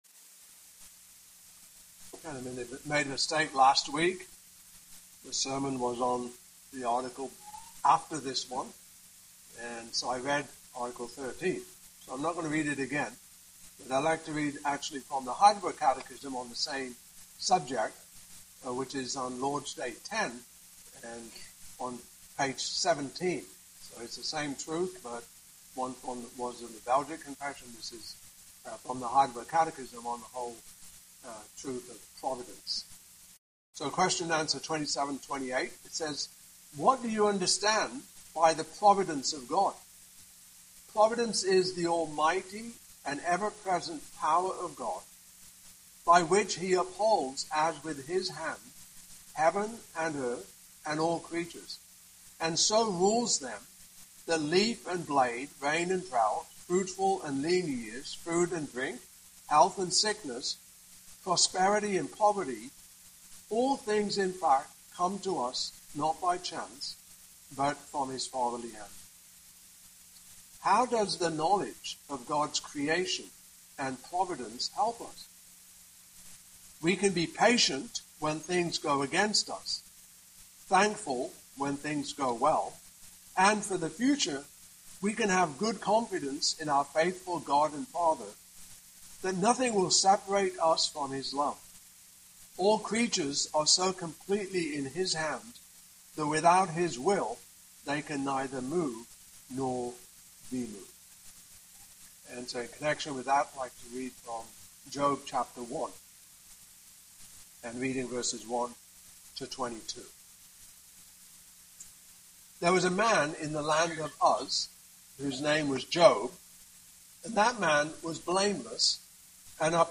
Passage: Job 1:1-22 Service Type: Evening Service